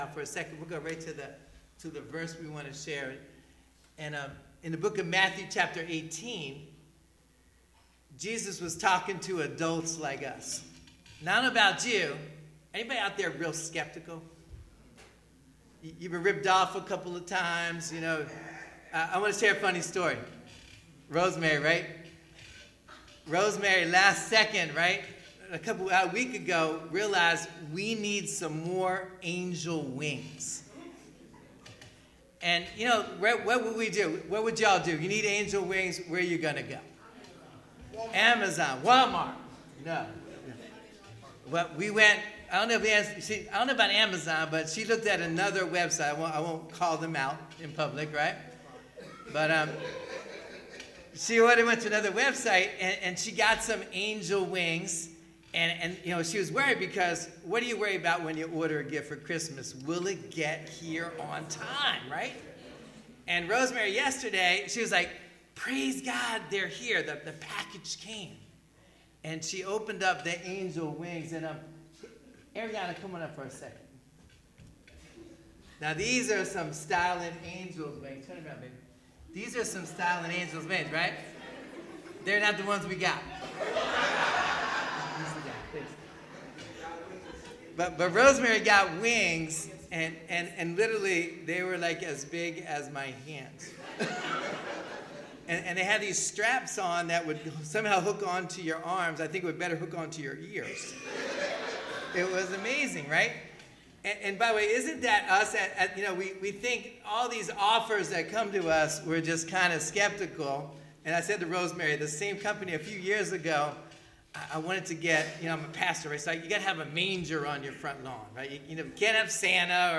FBC Children's Christmas Musical 2025